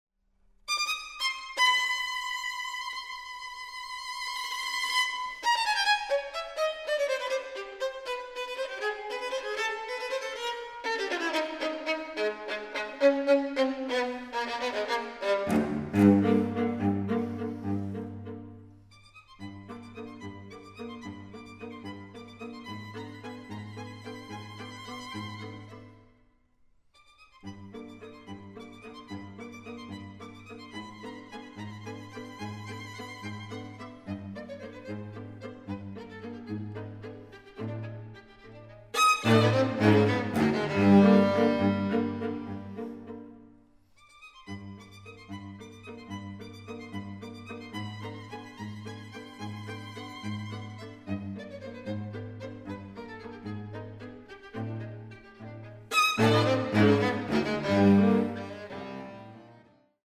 Streichquartett
Aufnahme: Festeburgkirche Frankfurt, 2024